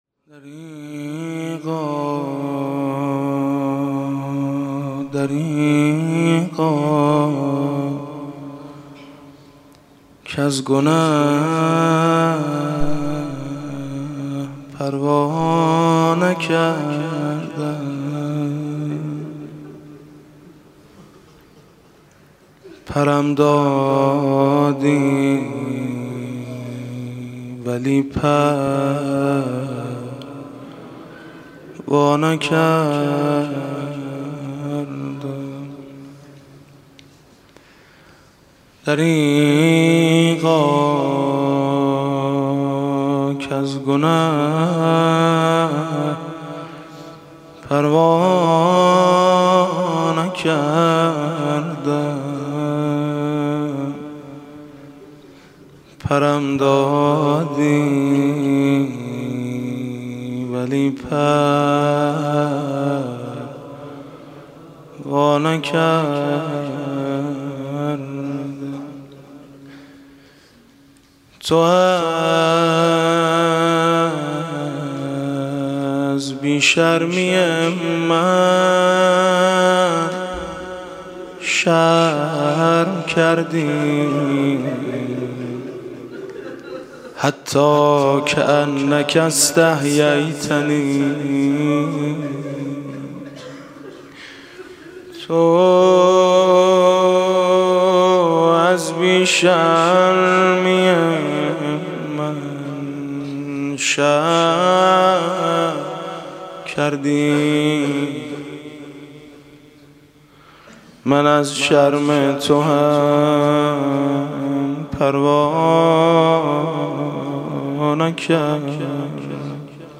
ادامه دعاهای خودجوش مردمی برای شفای آیت‌الله مهدوی‌کنی مداحی مطیعی - تسنیم
دانشجویان دانشگاه امام صادق(ع) نیز در این راستا، مجلس قرائت دعای توسل برگزار کرده است.